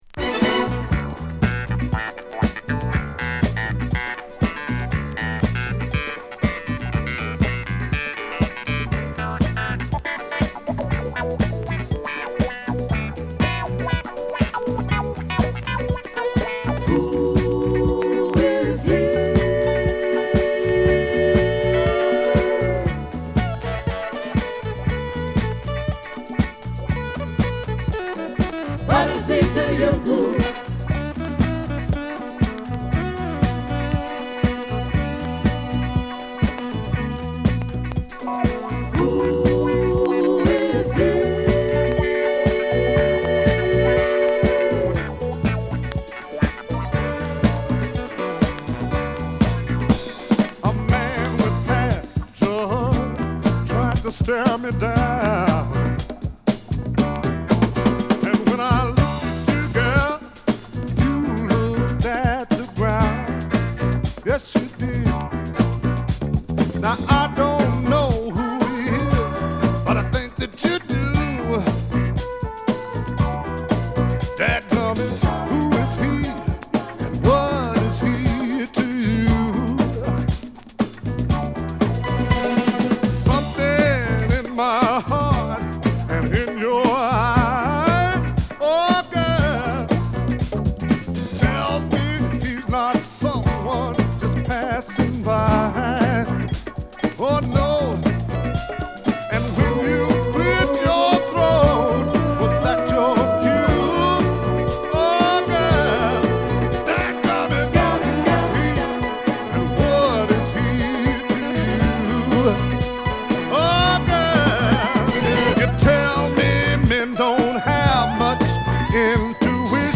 ＊プチノイズ出ます。
(Vocal)